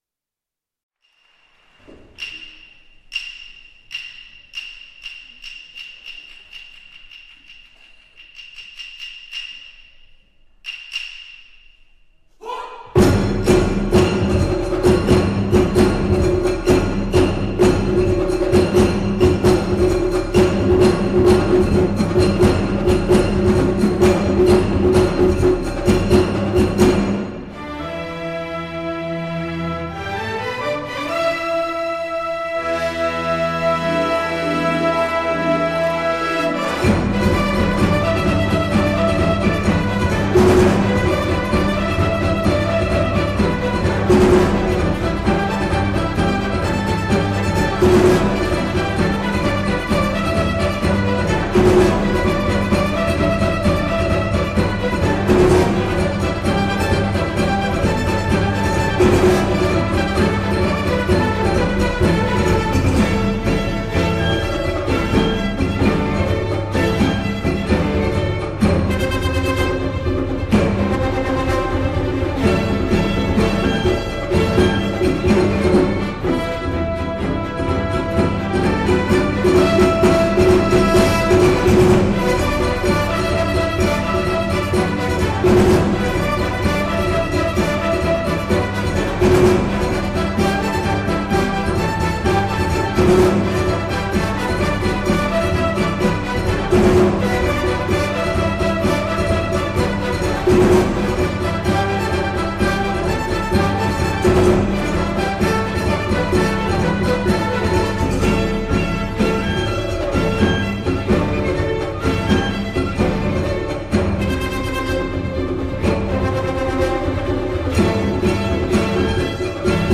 管弦楽